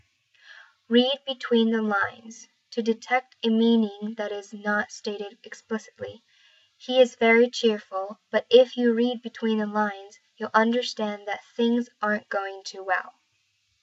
英語ネイティブによる発音は下記のリンクをクリックしてく ださい。